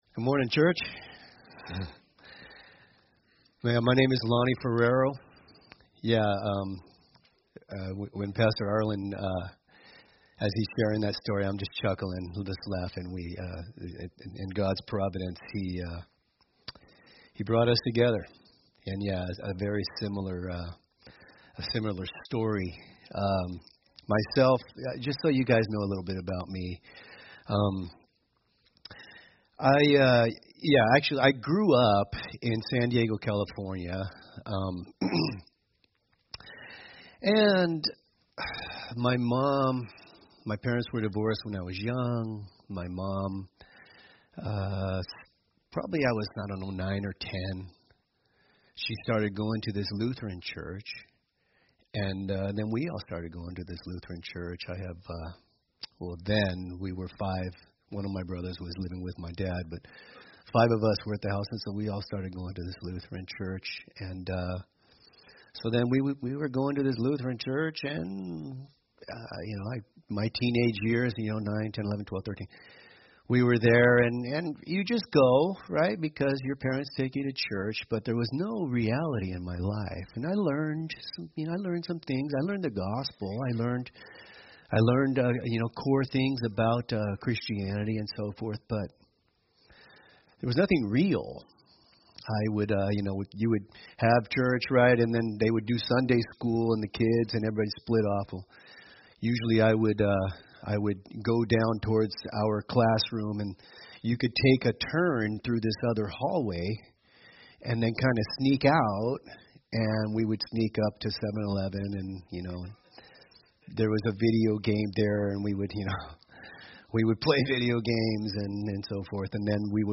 From Topics: "Guest Speakers"